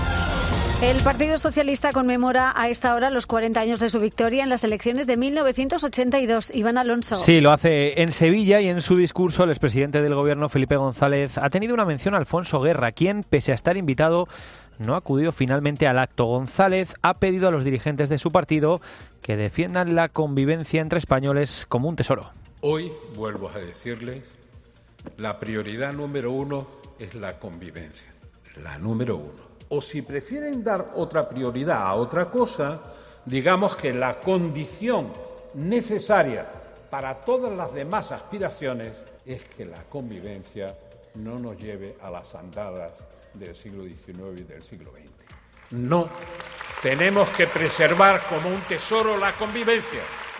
"Trato de buscar y lamento no conseguirlo a este personaje singular que levantaba mi mano en la ventana del Palace, que era Alfonso Guerra", ha dicho González, lo que ha provocado los aplausos de los asistentes al acto antes de añadir: "Y lo quiero tener en esta mano".